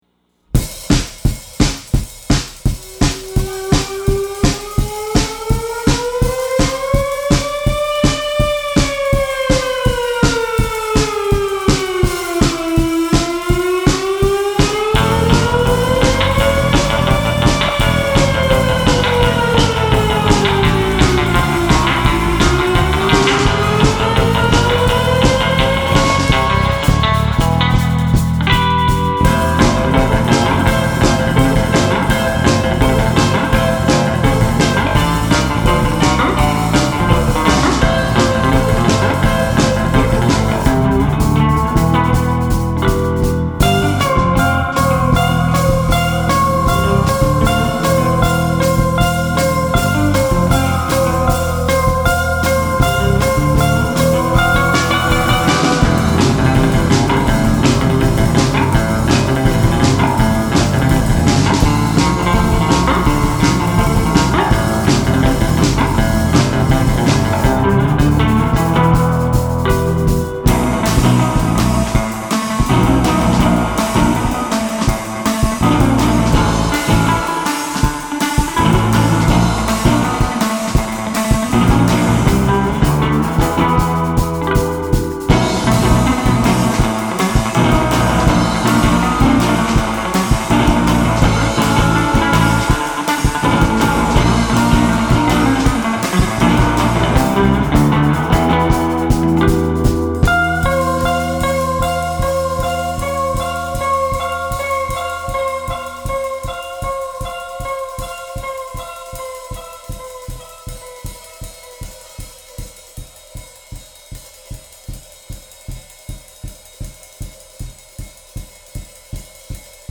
guitar.
drums.
bass.